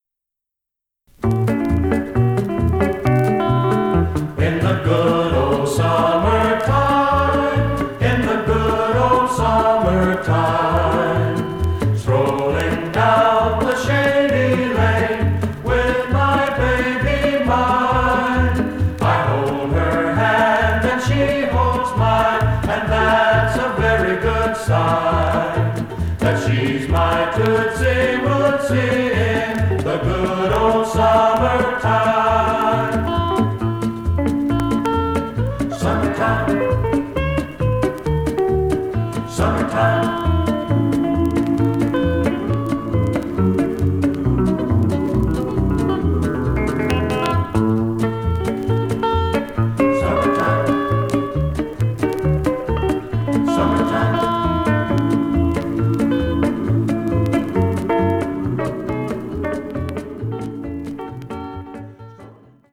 This song is in 3/4 waltz time.
Listen to Chet Atkins perform "In The Good Old Summer Time" (mp3)